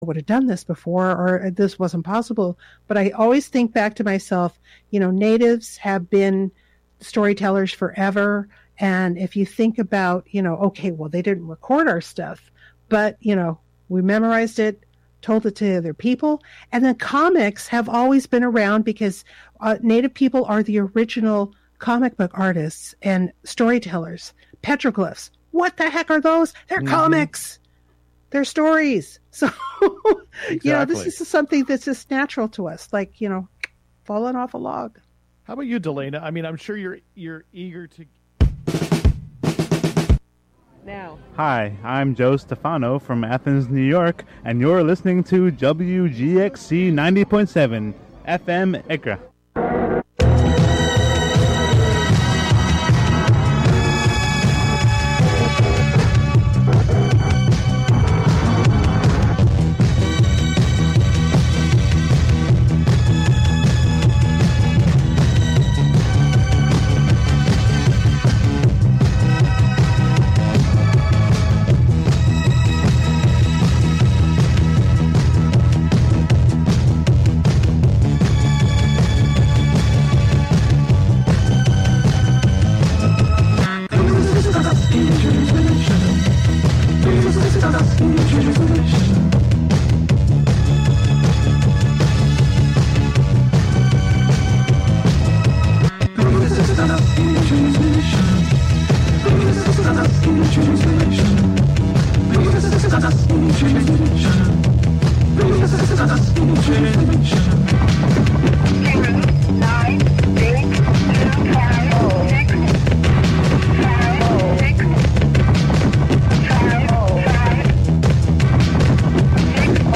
Contributions from many WGXC programmers.
This week's episode of the radio serial "I Have Seen Niagara" is featured: S3 EP3 - Psychic Network Niagara, part 3; True Fortunes A drifter comes to Niagara Falls seeking his true fortune told.
The "WGXC Morning Show" is a radio magazine show featuring local news, interviews with community leaders and personalities, reports on cultural issues, a rundown of public meetings and local and regional events, with weather updates, and more about and for the community, made mostly through volunteers in the community through WGXC.
Sometimes national, state, or local press conferences, meetings, or events are also broadcast live here.